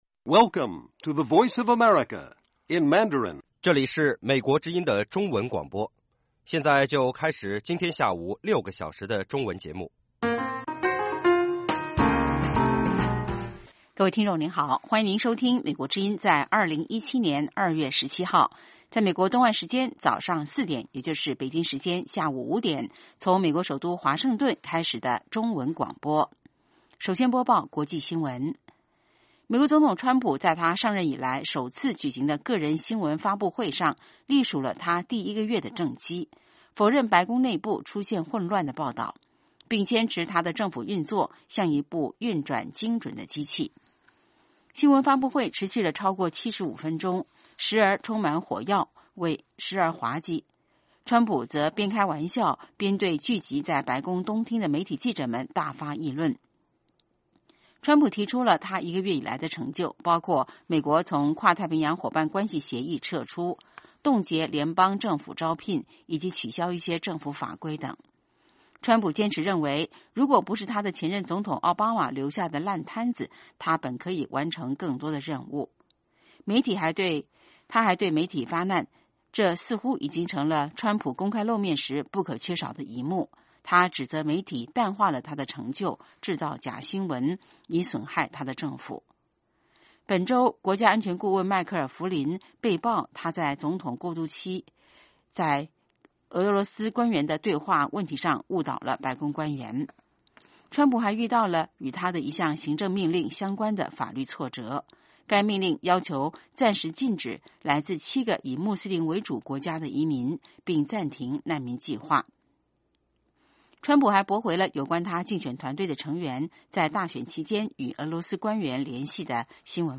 北京时间下午5-6点广播节目。广播内容包括国际新闻，美语训练班(学个词， 美国习惯用语，美语怎么说，英语三级跳， 礼节美语以及体育美语)，以及《时事大家谈》(重播)